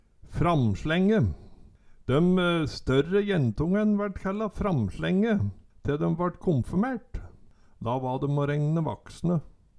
framslenge - Numedalsmål (en-US)
Høyr på uttala Ordklasse: Substantiv hokjønn Kategori: Kropp, helse, slekt (mennesket) Karakteristikk Attende til søk